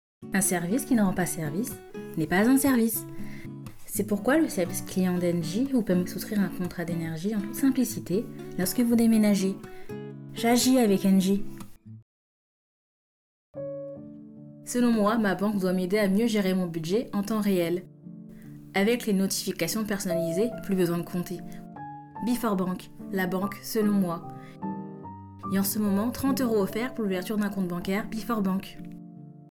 Bande voix
Voix off
17 - 28 ans - Soprano